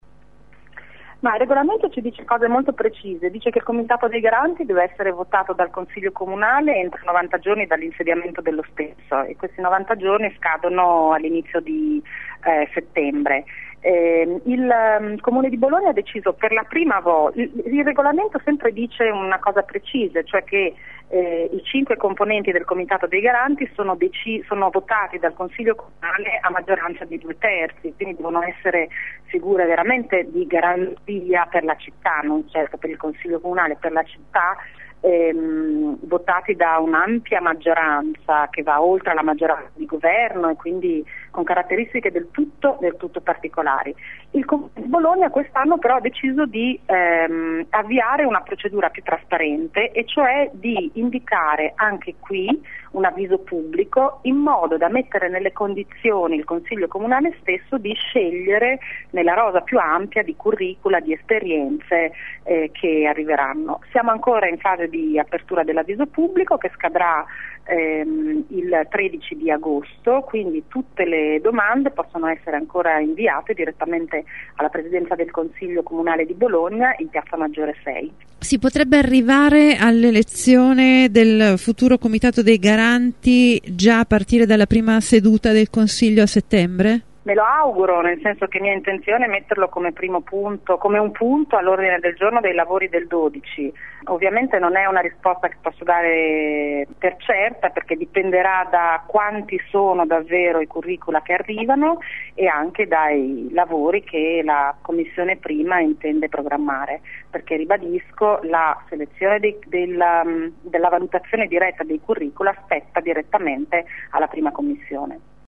Simona Lembi, presidente del Consiglio Comunale: